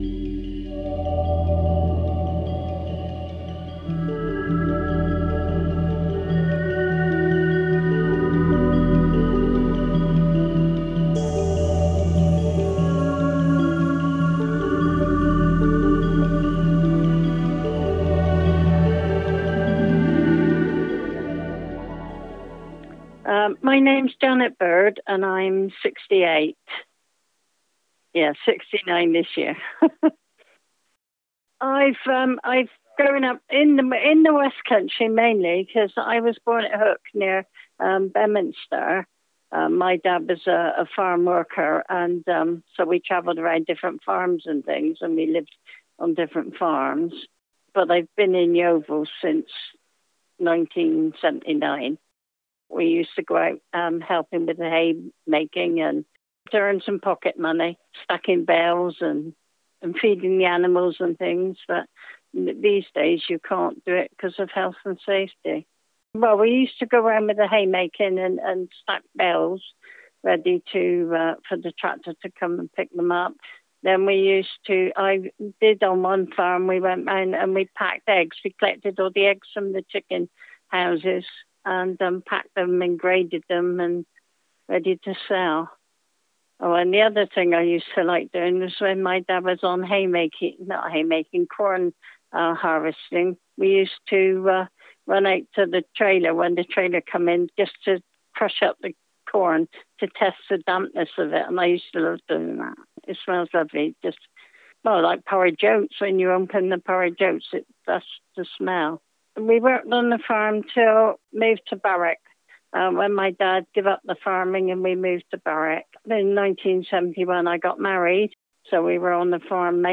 interviewed residents and staff from Somerset Care and local elders from Yeovil